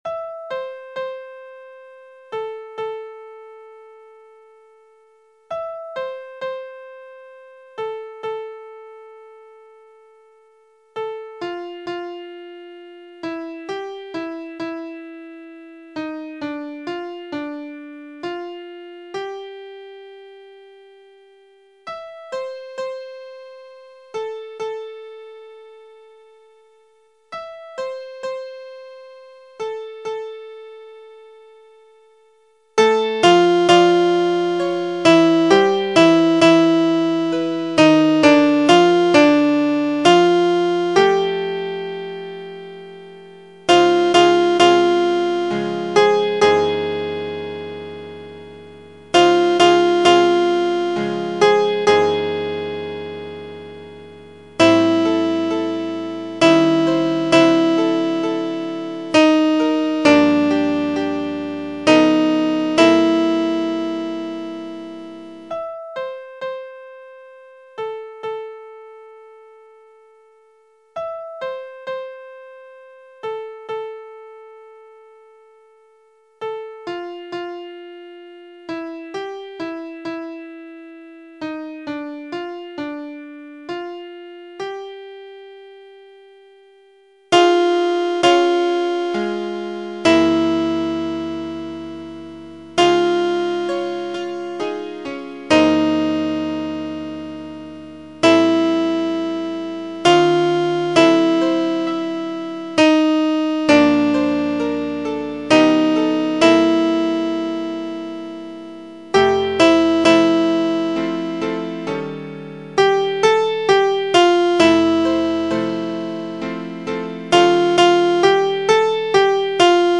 Christmas Time is Here (arr. Alexander)      Your part emphasized:     Sop 1   Sop 2
Alto 2